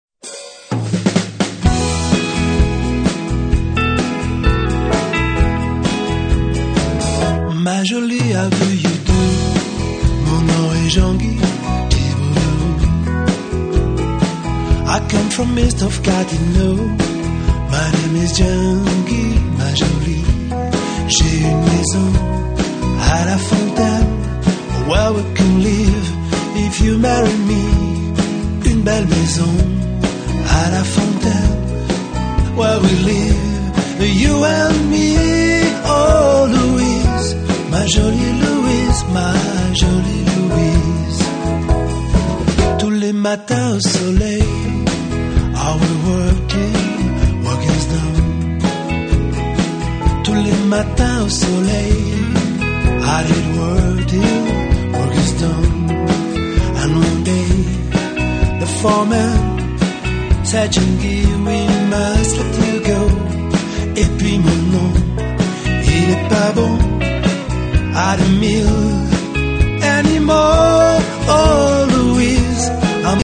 19 titres de rock n’ roll Francais revisit?s